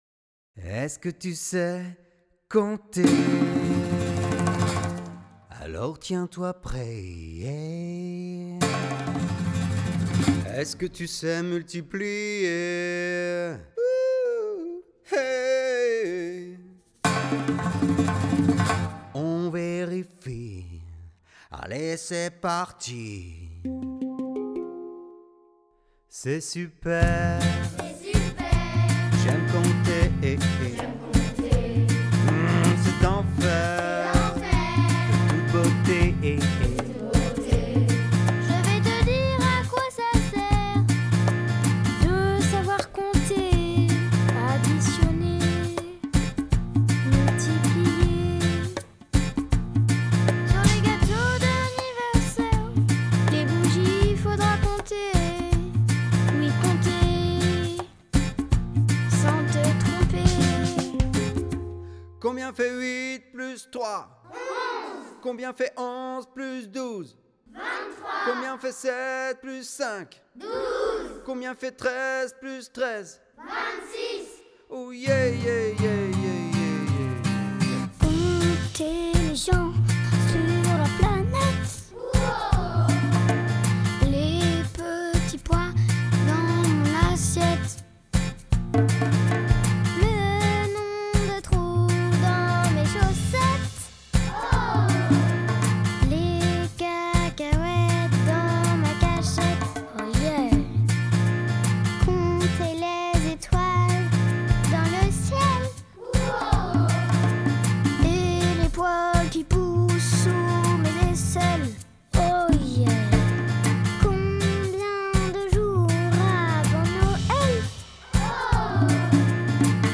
1 – Est-ce que tu sais compter ? (CE2-CM1) :